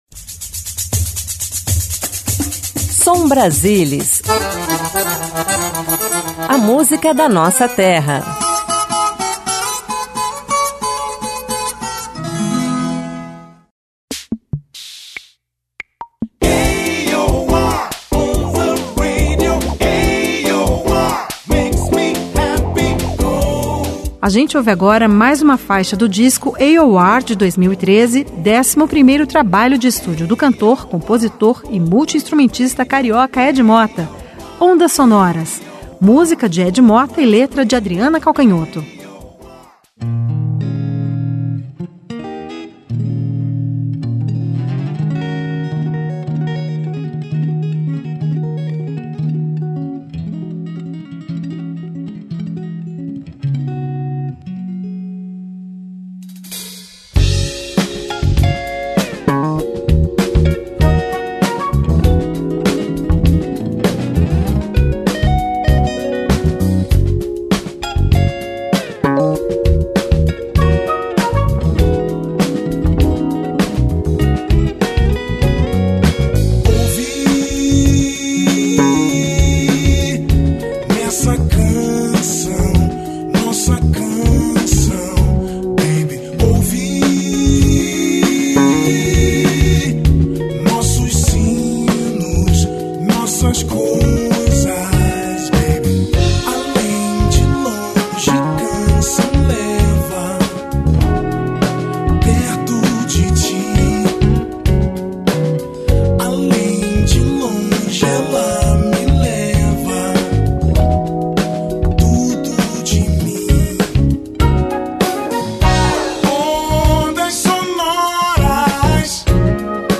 Soul Rhythm and blues Rock Bossa Nova Disco Reggae Música instrumental Jazz Pop Blues Samba Funk rock Salsa Latin jazz